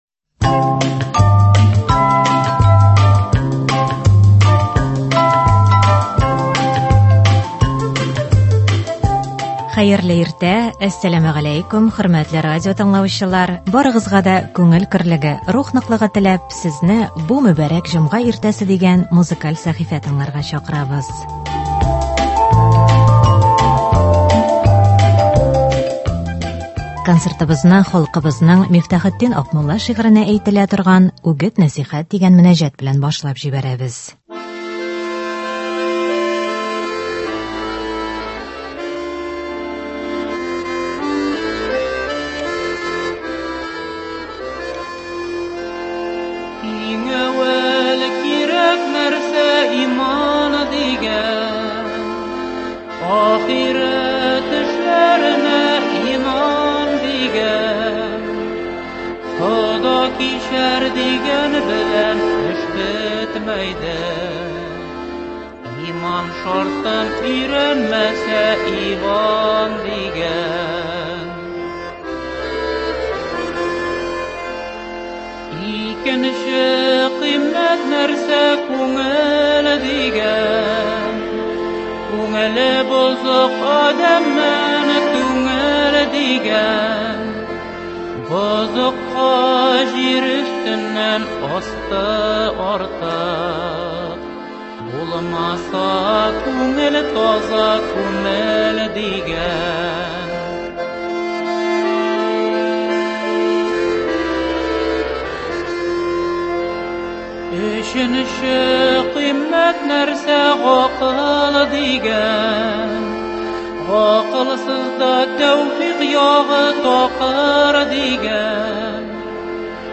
Җомга иртәсе өчен иң моңлы җырлар!